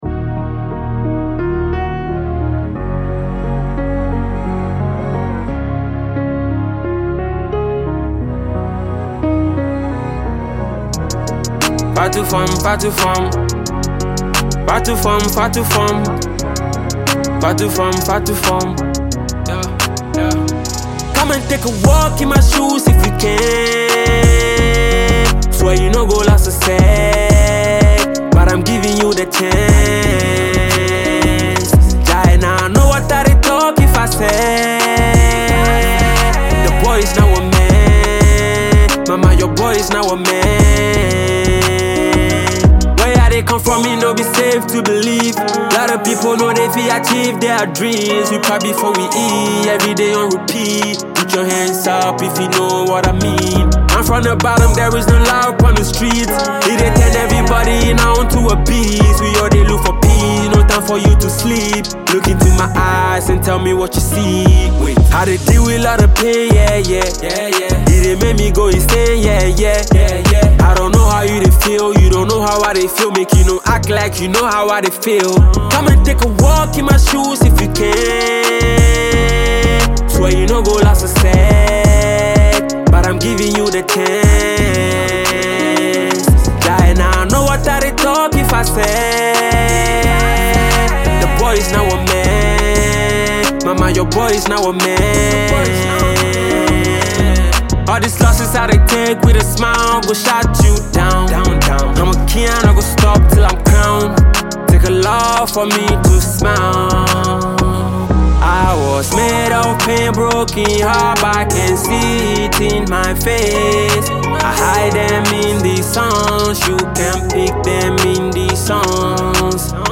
Ghana MusicMusic
Ghanaian rapper and singer